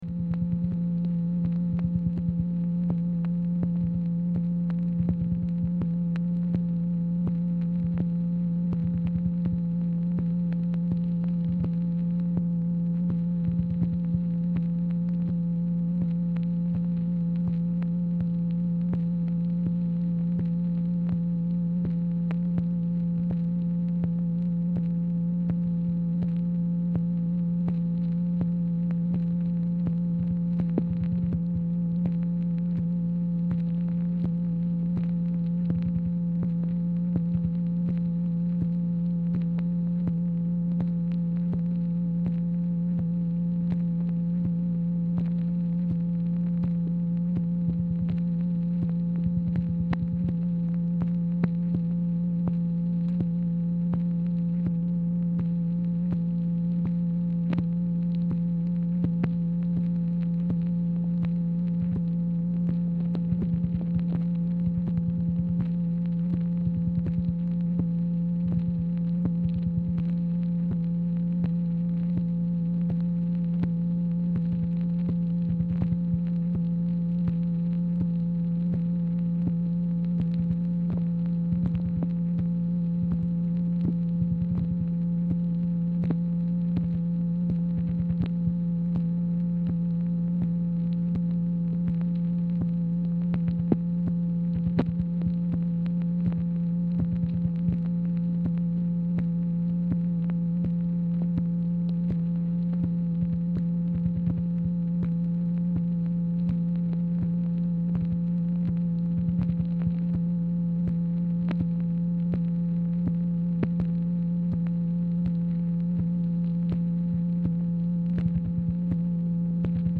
Telephone conversation # 10034, sound recording, MACHINE NOISE, 4/19/1966, time unknown | Discover LBJ
Format Dictation belt